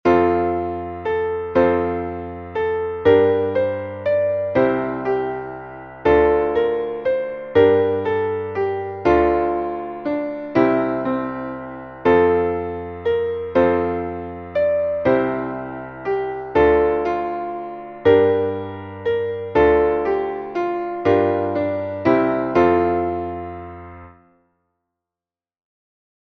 Traditionelles Weihnachtslied